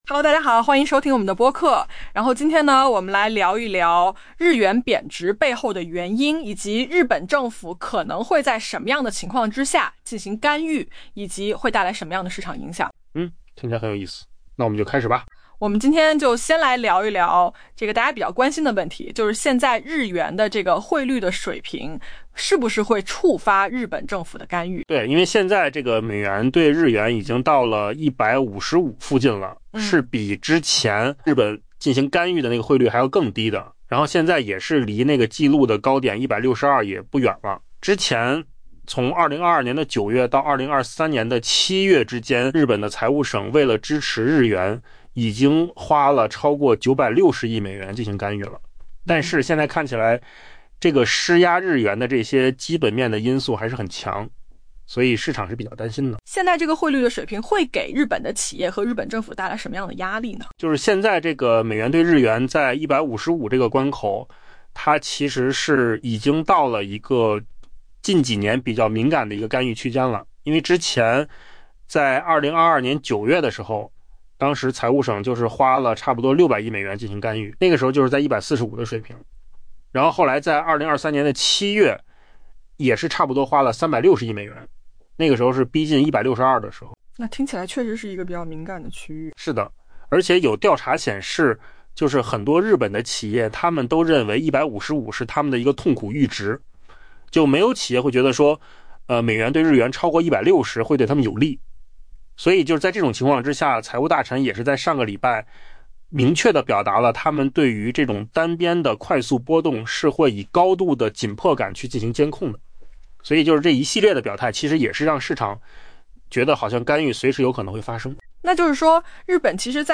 AI 播客：换个方式听新闻 下载 mp3 音频由扣子空间生成 日元汇率下跌，引发市场对日本出手干预以减缓或逆转跌势的担忧。